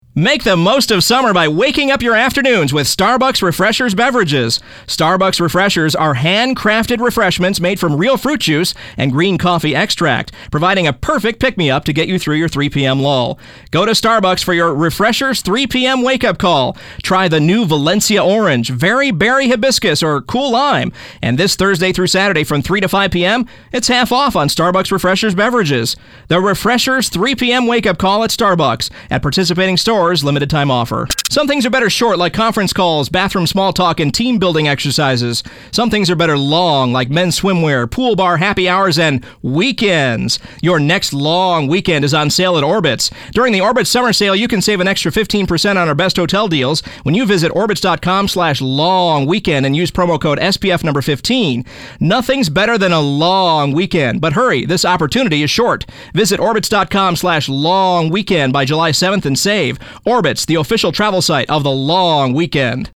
VOICE-OVER DEMO
•Young, hip sounding voice that cuts through